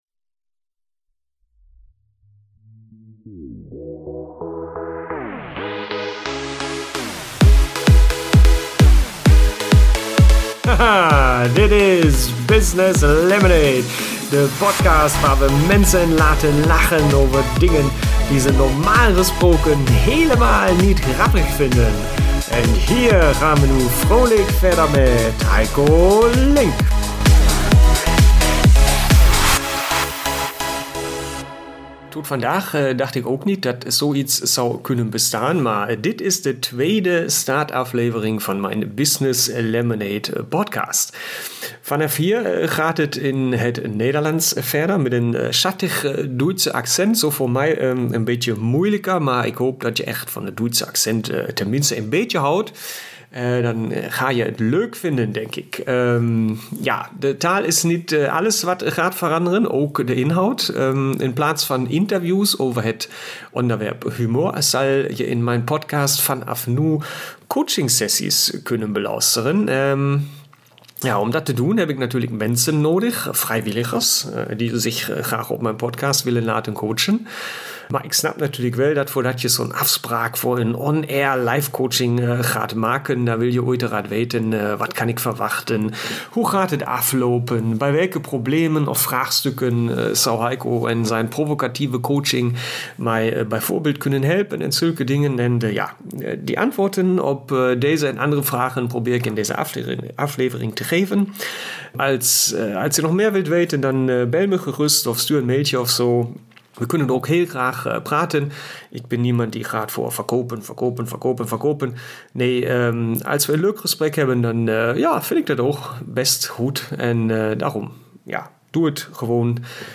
Vanaf hier gaat het in het Nederlands – met een heel schattig Duitse accent - verder en ook met nieuwe inhoud.